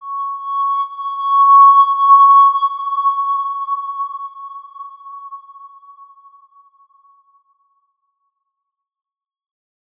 X_Windwistle-C#5-mf.wav